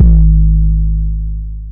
SouthSide Kick Edited (63).wav